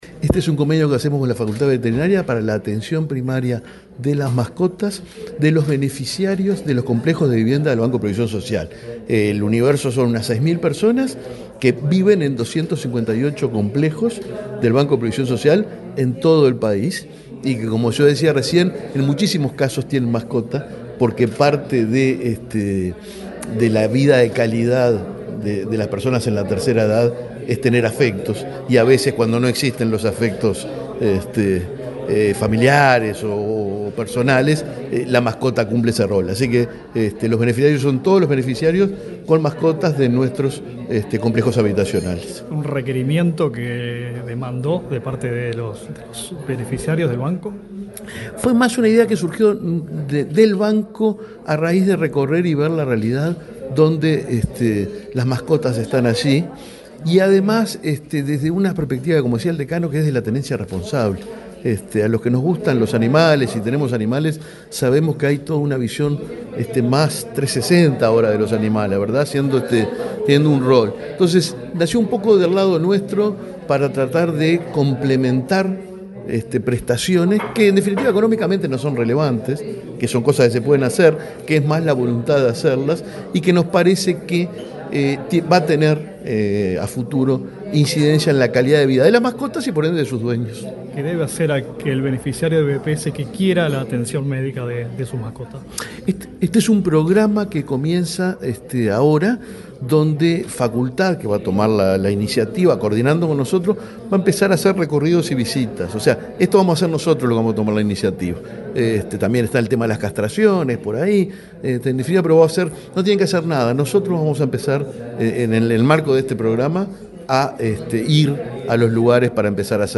Entrevista al presidente el BPS, Alfredo Cabrera
Entrevista al presidente el BPS, Alfredo Cabrera 26/08/2024 Compartir Facebook X Copiar enlace WhatsApp LinkedIn El Banco de Previsión Social (BPS) y la Facultad de Veterinaria de la Udelar firmaron un convenio para la asistencia primaria de las mascotas de beneficiarios de soluciones habitacionales destinadas a jubilados y pensionistas. El presidente del BPS, Alfredo Cabrera, explicó a Comunicación Presidencial el alcance del acuerdo.